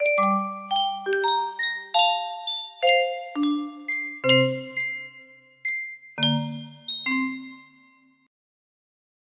TKAI midi music box melody
Full range 60